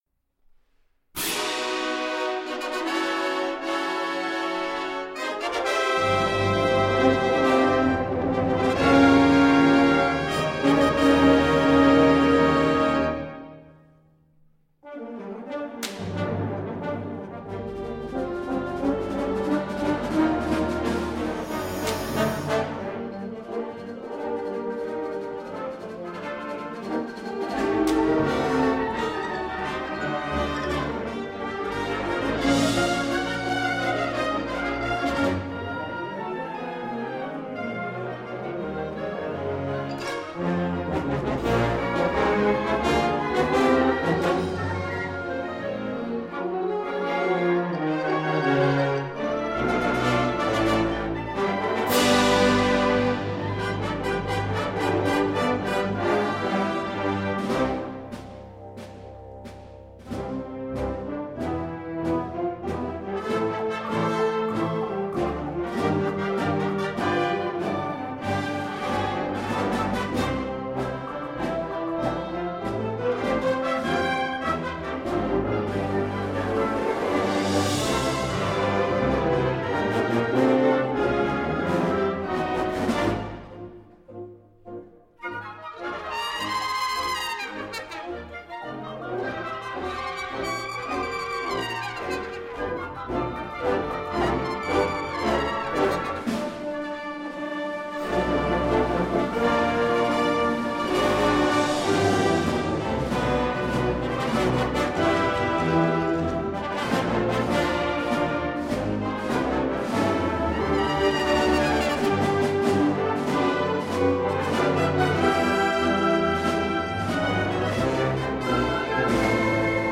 A Period Piece for Bands of Winds